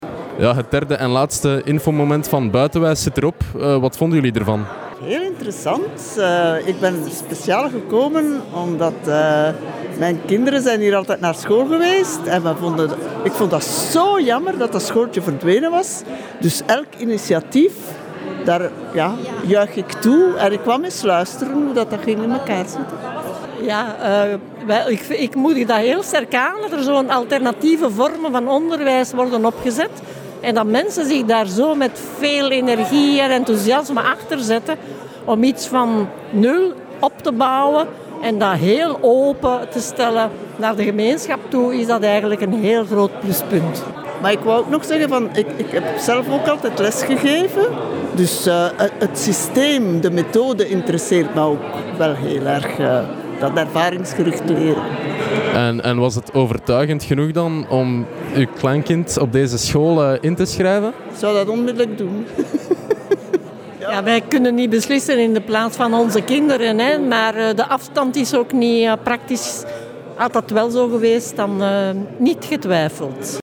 Het derde infomoment van BuitenWijs vond plaats in Ontmoetingscentrum Neigem op woensdag 19 april.
Beluister hieronder het interview met twee enthousiaste grootouders:
interview-grootouders-BuitenWijs.mp3